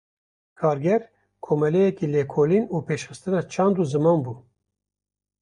Pronounced as (IPA)
/leːkoːˈliːn/